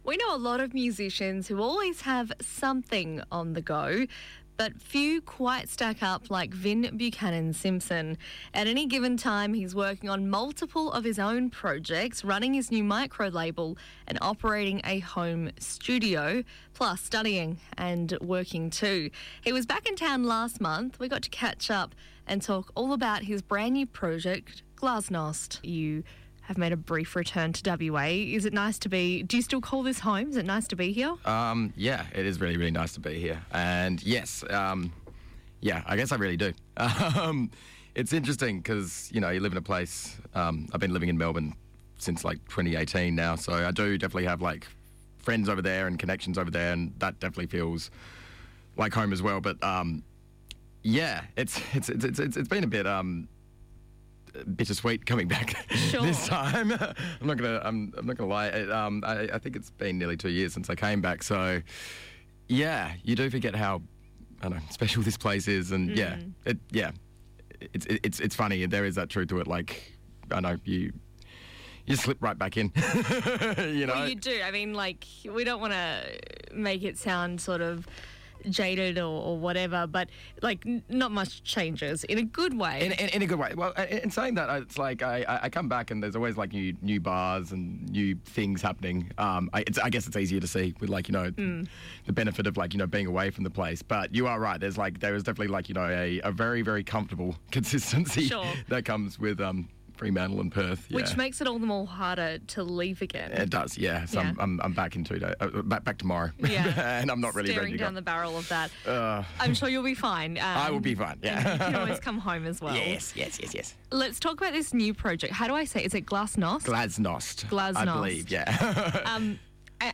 Glas-Nost-interview.mp3